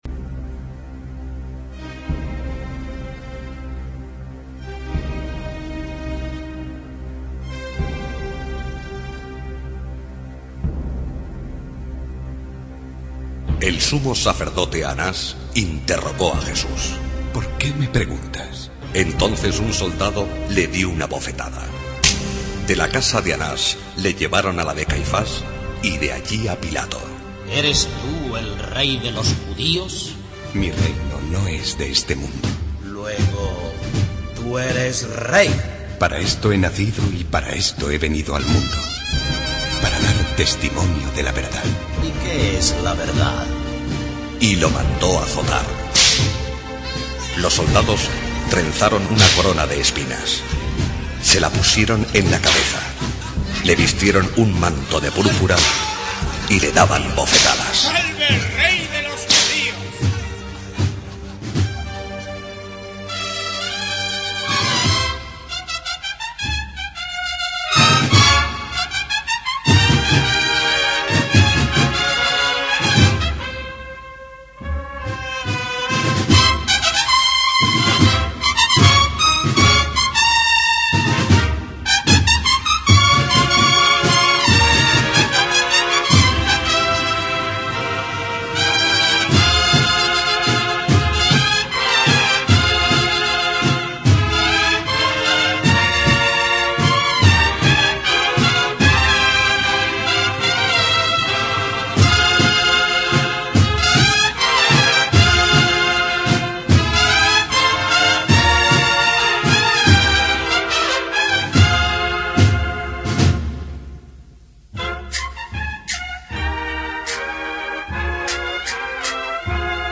Temática: Cofrade
una extraordinaria marcha
una magnífica Sevillana en voces